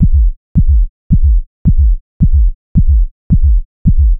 Index of /90_sSampleCDs/Trance_Formation/Atmospheric
25_Synth_Heartbeat.WAV